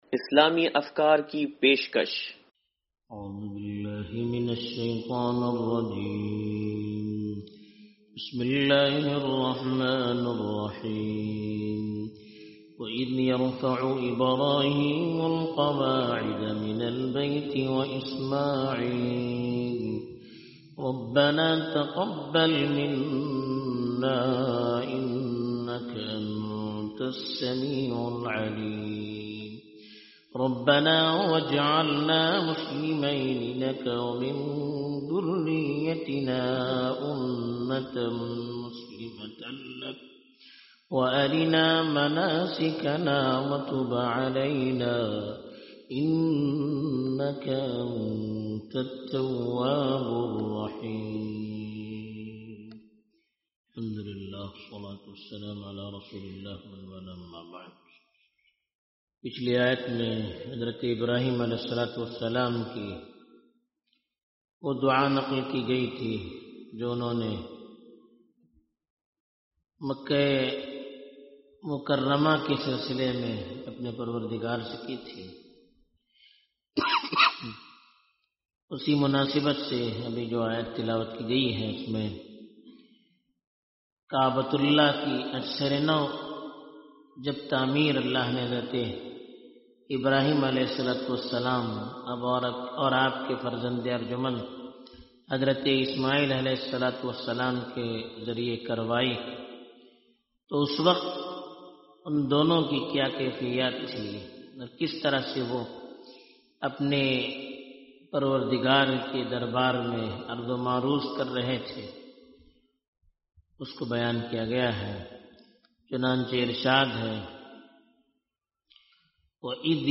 درس قرآن نمبر 0089